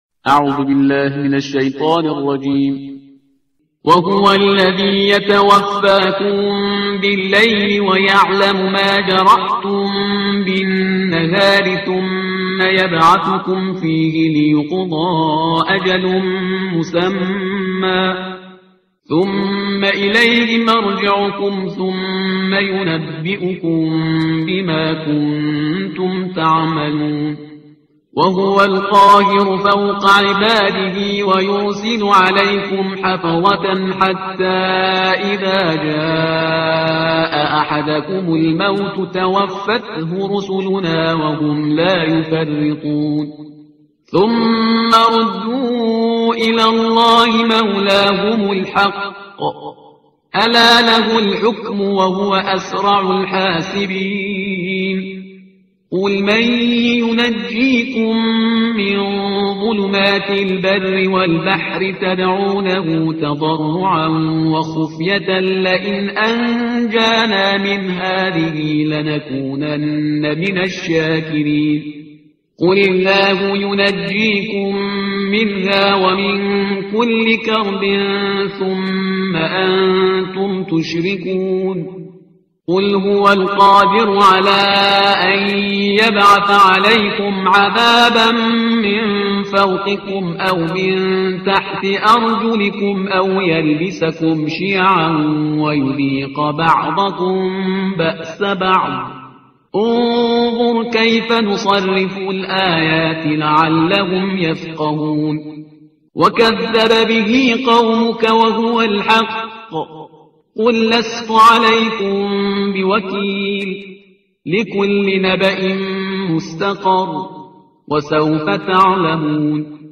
ترتیل صفحه 135 قرآن با صدای شهریار پرهیزگار